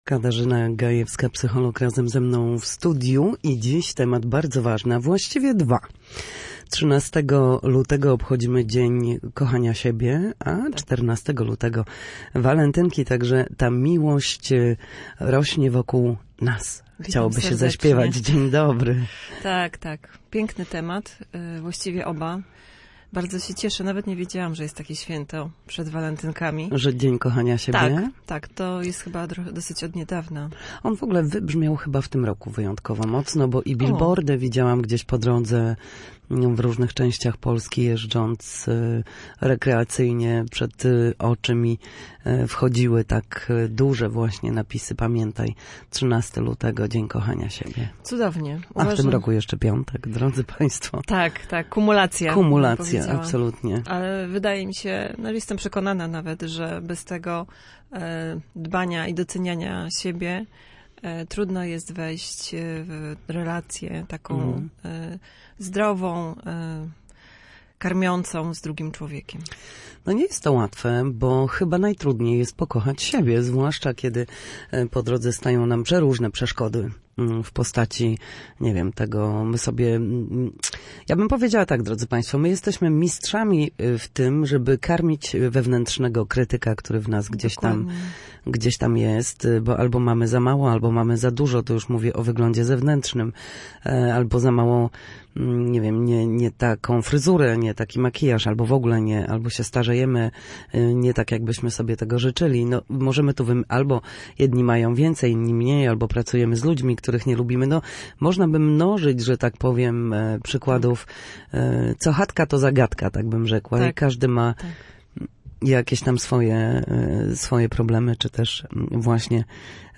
W każdą środę, w popołudniowym paśmie Studia Słupsk Radia Gdańsk, dyskutujemy o tym, jak wrócić do formy po chorobach i
W audycji „Na zdrowie” nasi goście – lekarze i fizjoterapeuci – odpowiadają na pytania dotyczące najczęstszych dolegliwości, podpowiadają, jak wyleczyć się w domowych waru